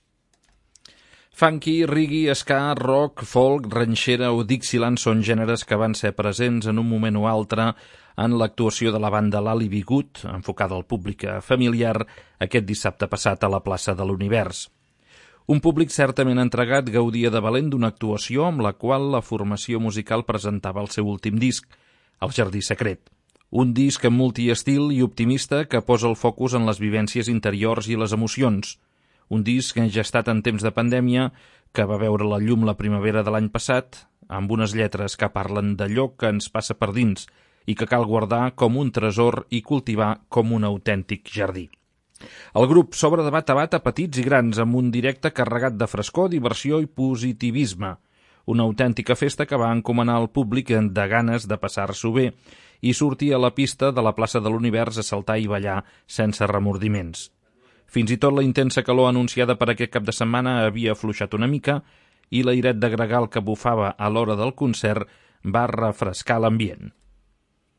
Funky, reggae, ska, rock, folk, ranxera o dixieland